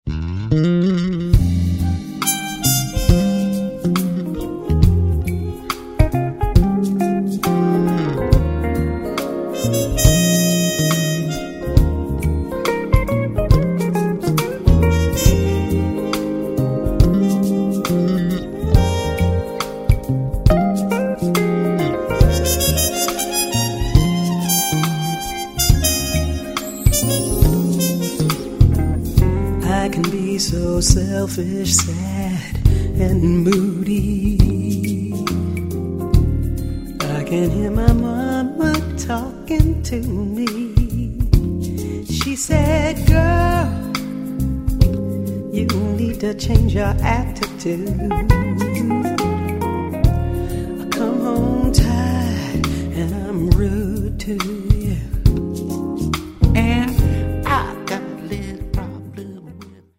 na fortepianie